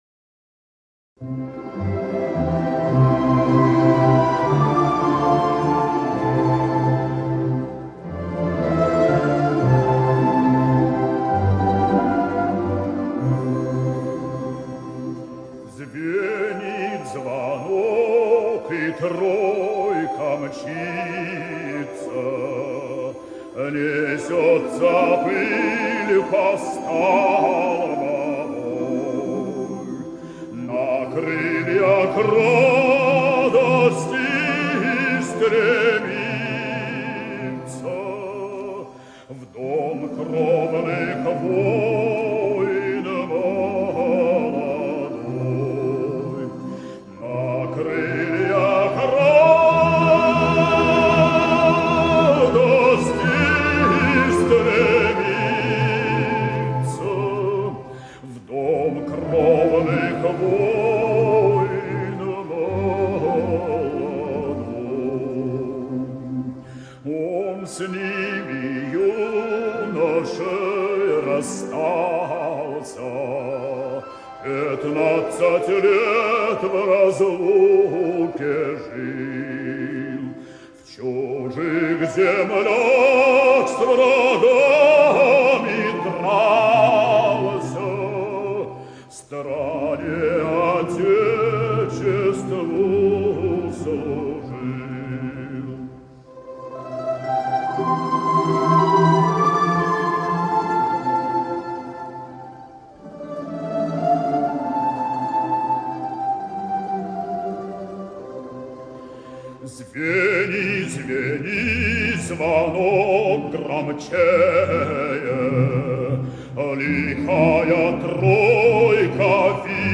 Иван Скобцов - Звенит звонок (народная - Г.Малышев - 1973г)
К сожалению, эти оба варианта - профессиональны.